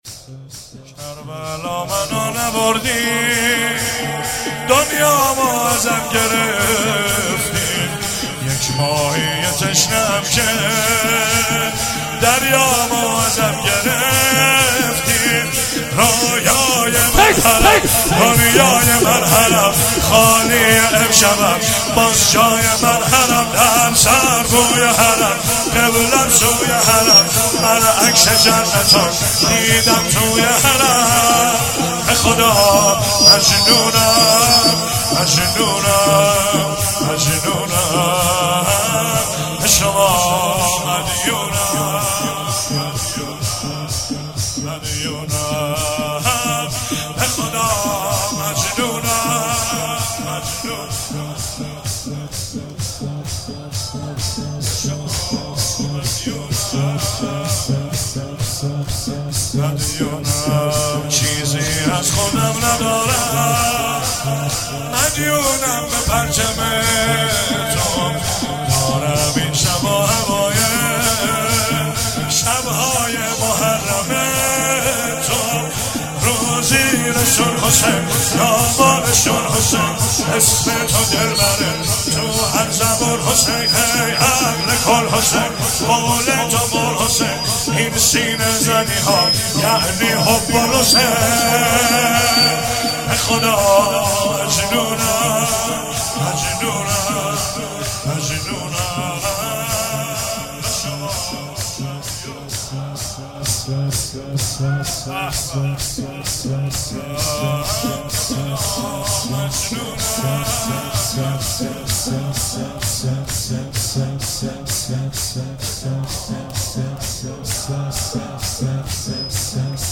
شب سوم رمضان 95، حاح محمدرضا طاهری
04 heiate alamdar mashhad alreza.mp3